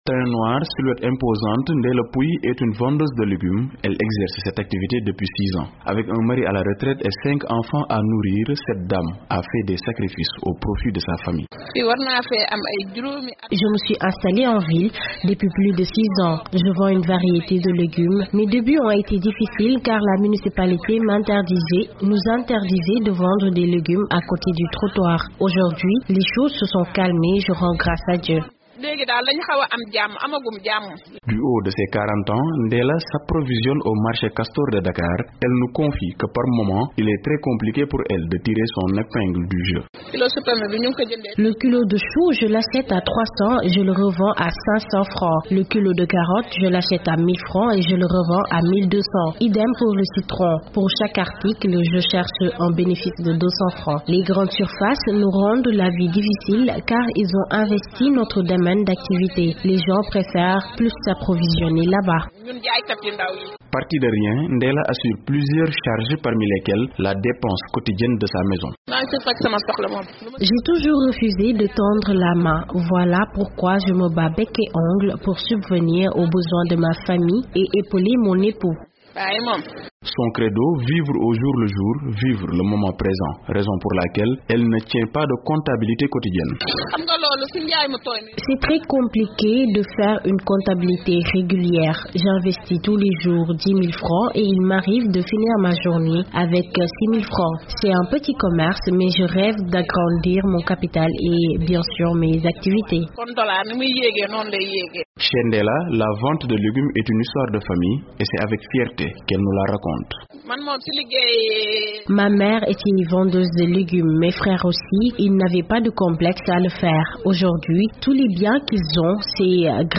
Pour la journée de la femme, nous vous proposons une série de reportages portraits au Sénégal sur des femmes dites "gagne peu" qui gagnent difficilement leur vie mais qui parviennent à nourrir leurs familles grâce à leurs activités.